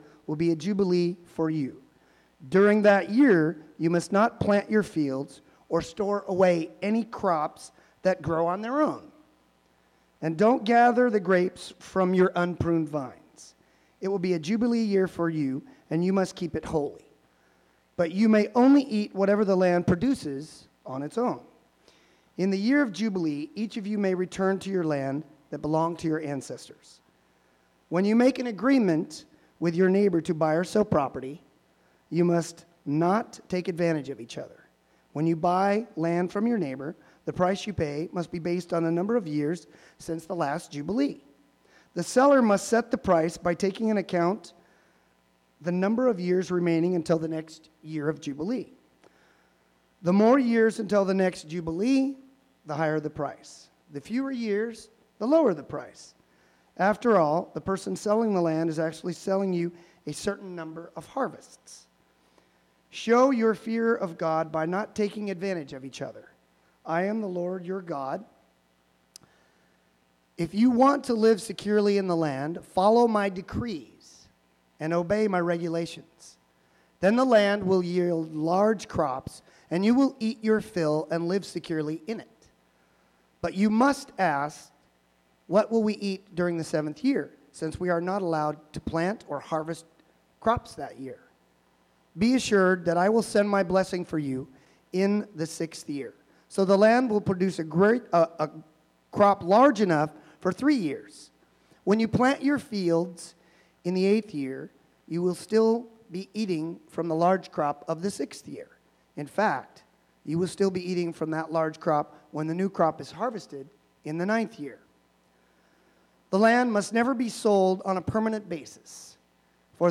Bible Text: Leviticus 25:3-20 | Preacher: